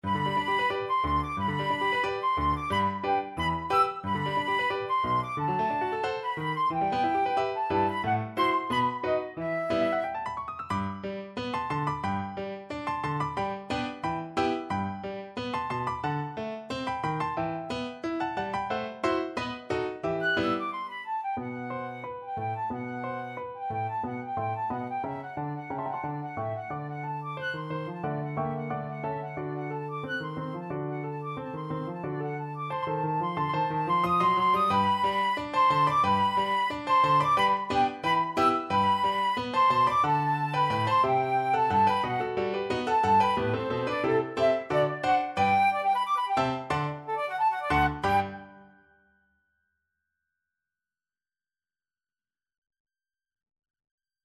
Classical Brahms, Johannes Violin Concerto, Op.77, Third Movement (Main Theme) Flute version
~ = 100 Allegro giocoso, ma non troppo vivace =90 (View more music marked Allegro giocoso)
G major (Sounding Pitch) (View more G major Music for Flute )
2/4 (View more 2/4 Music)
A5-F#7
Flute  (View more Intermediate Flute Music)
Classical (View more Classical Flute Music)
brahms_violin_concerto_3rd_FL.mp3